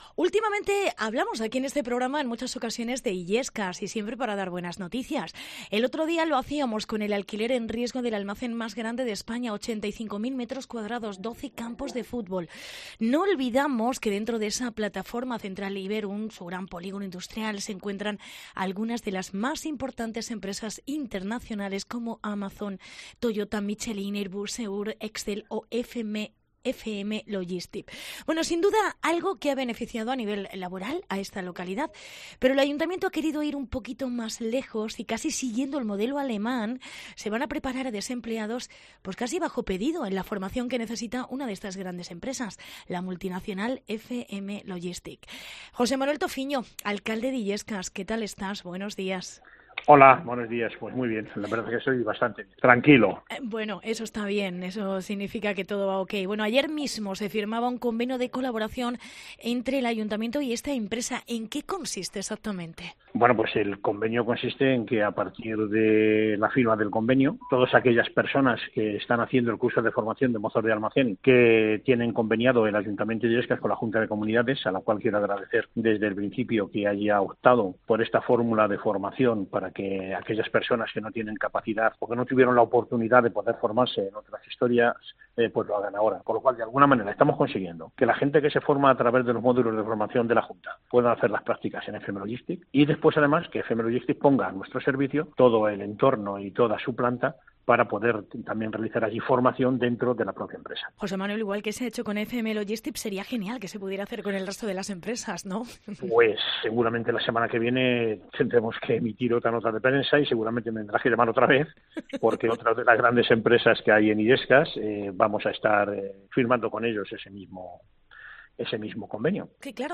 Entrevista José Manuel Tofiño, alcalde de Illescas